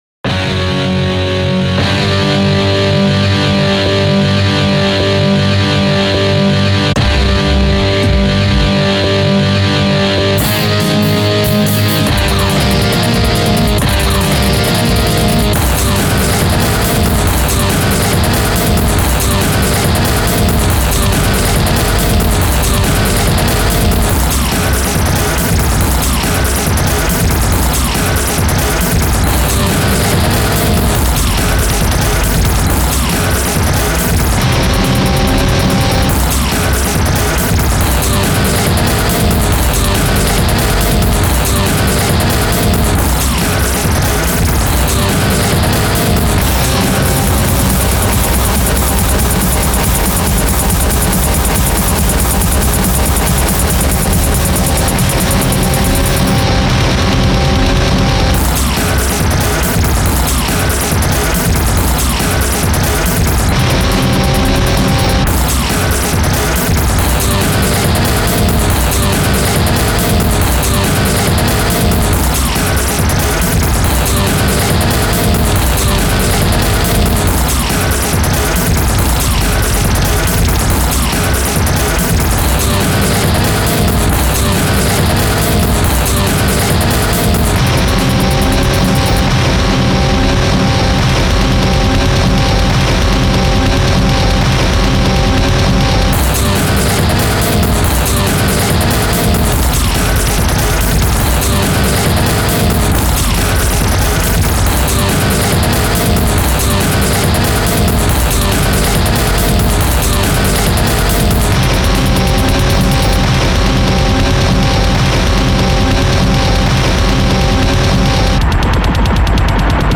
Aggressive music [mp3] | Project Perfect Mod
Well, my usual ambient stuff wouldn't work, so I started practicing on fast agressive music.
Very. some tracks seem to have messed up endings like a long roll-out or a sample that keeps running, don't worry about it
Actually wait, the 2nd isn't ambient, my bad.